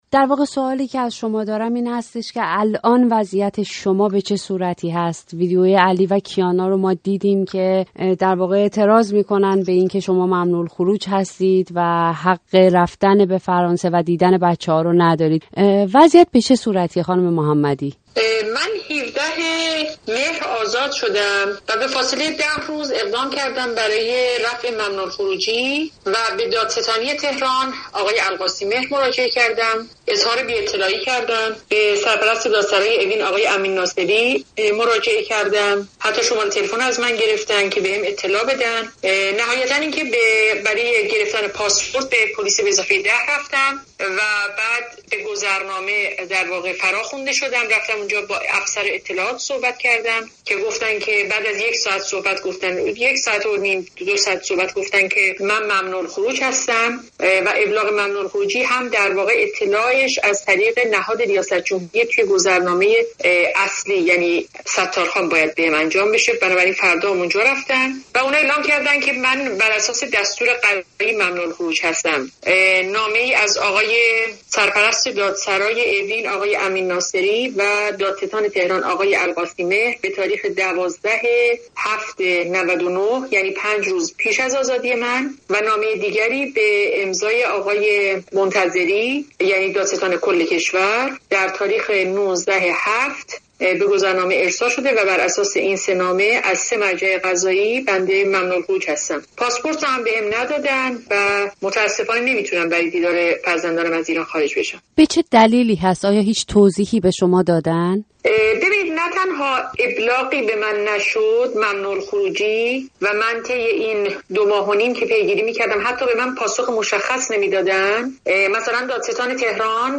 گفت‌وگوی اختصاصی با نرگس محمدی؛ «ممنوع الخروجم کرده‌اند»